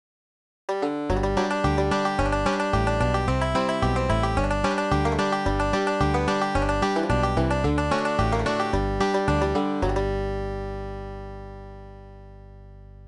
(Bluegrass - tab is based on Ralph
Scruggs style - G tuning)